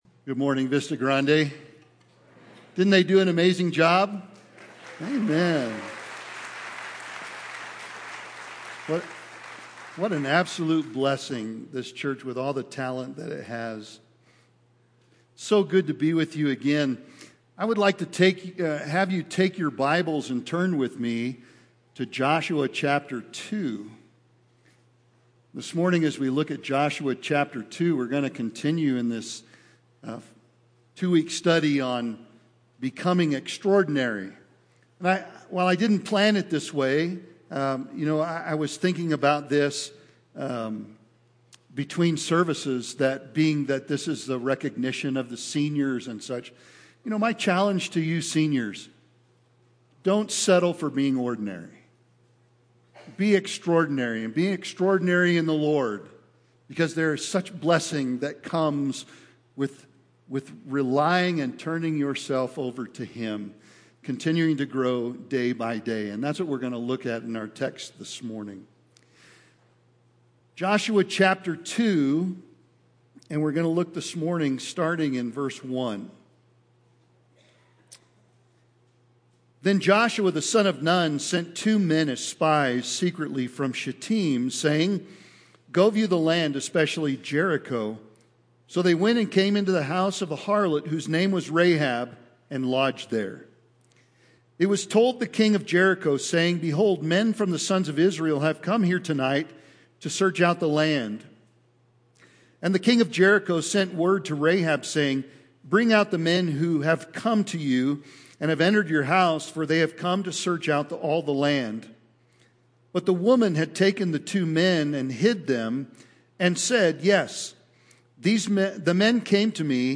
" Transition Sermons " Sermons by guest speakers and preachers through the transition.